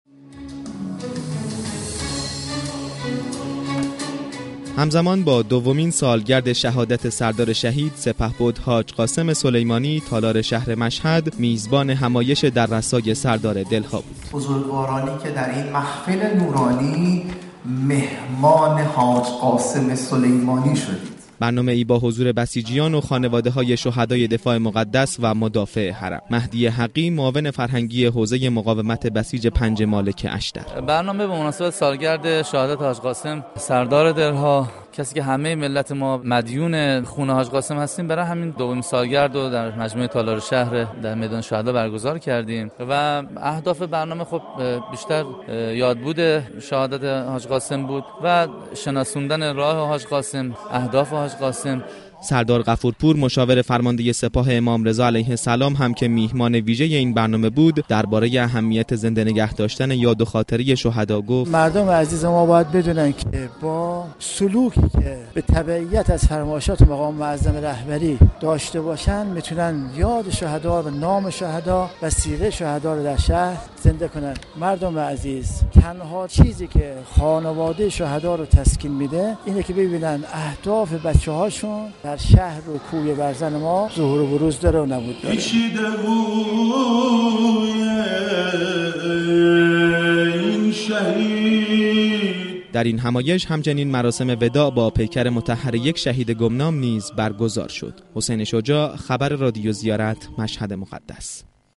اجتماع بزرگ فاطمی با عنوان در رثای سردار دل ها با حضور بسیجیان و جمعی از خانواده های معظم شهدا در مشهد مقدس برگزار شد.